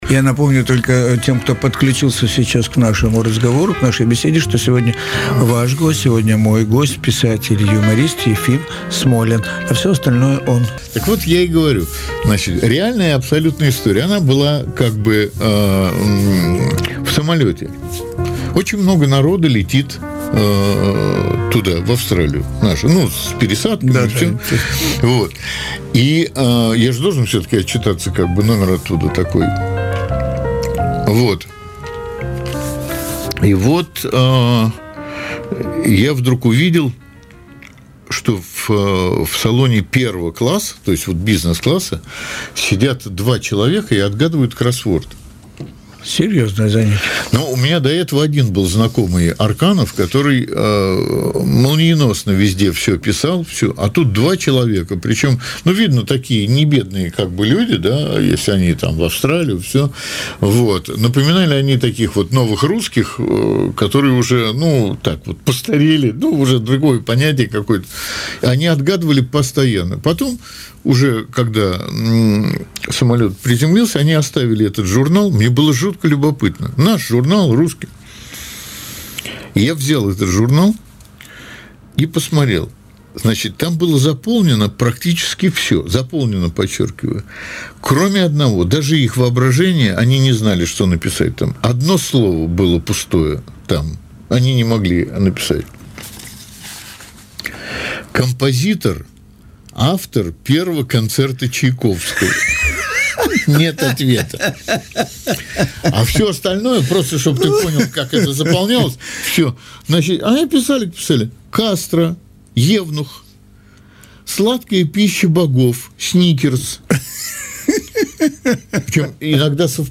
Фрагмент беседы Николая Тамразова с Ефимом Смолиным